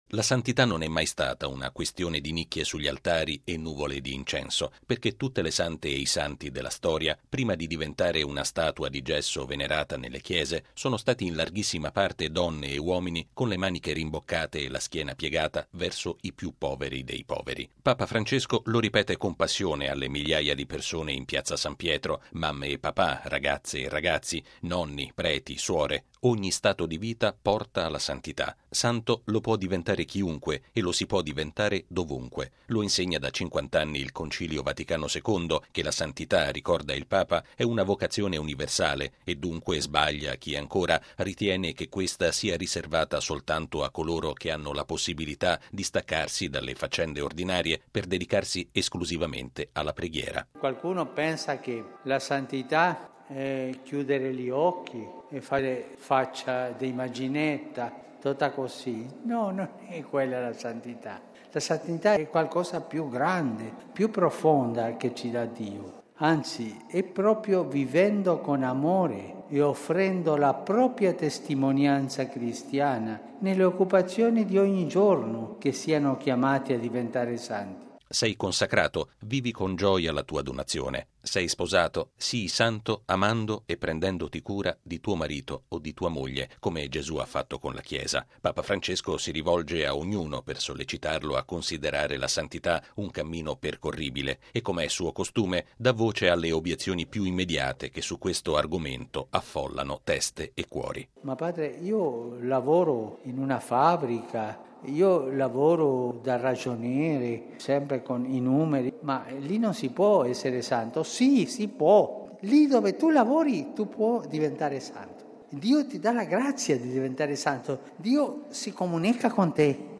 La santità è un cammino che tutti possono percorrere, in qualsiasi luogo si svolga la loro vita, e si costruisce con piccoli gesti fatti per amore degli altri. È l’insegnamento che Papa Francesco ha proposto alla folla radunatasi in Piazza San Pietro per l’udienza generale.